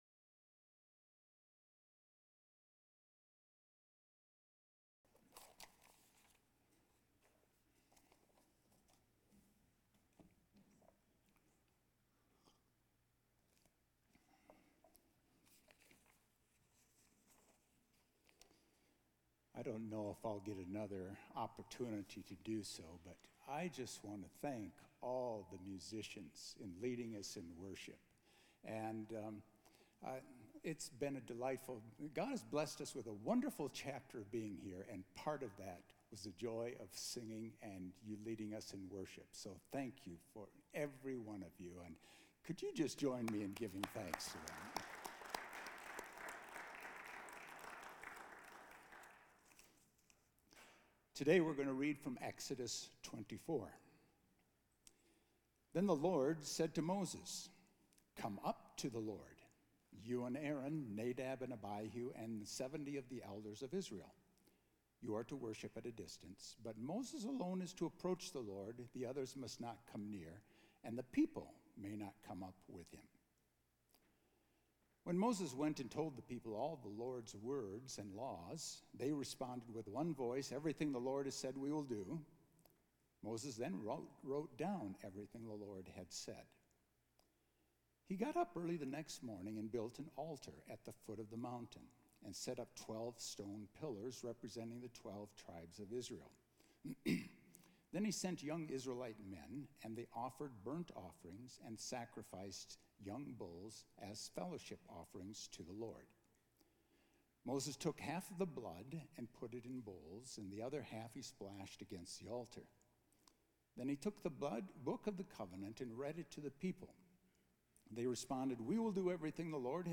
A message from the series "We are the Church."